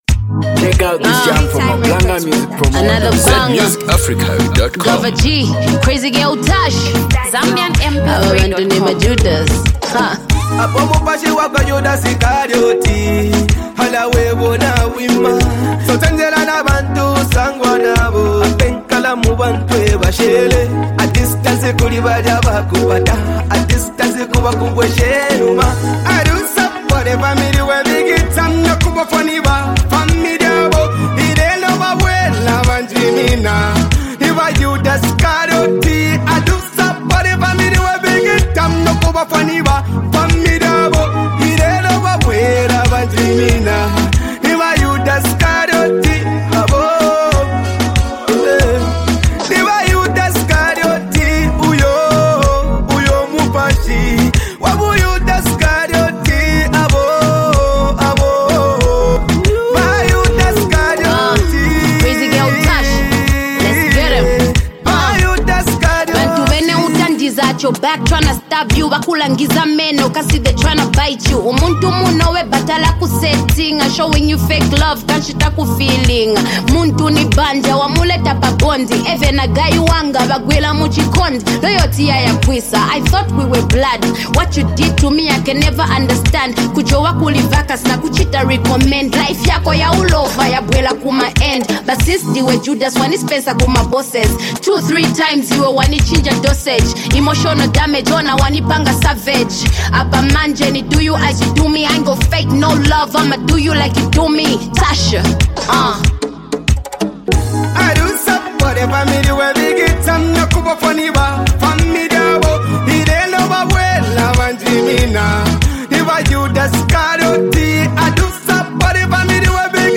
gifted female MC